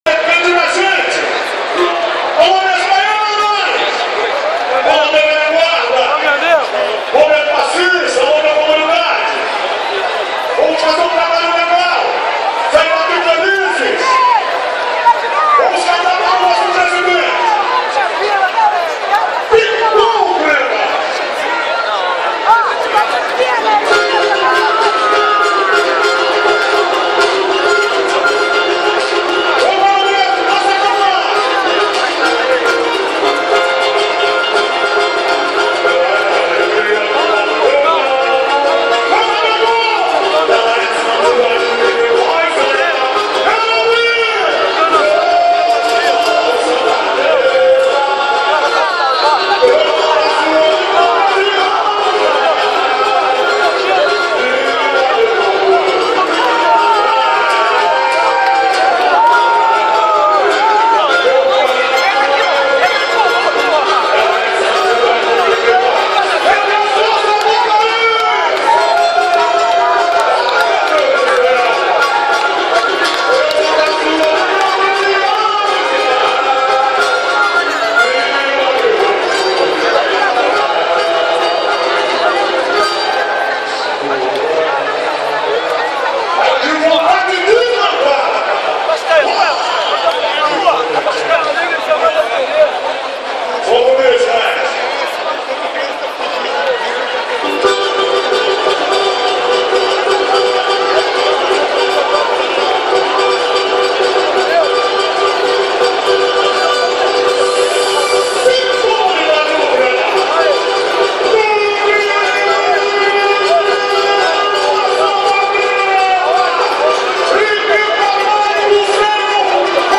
A bateria, apesar dos percalços, passou bem.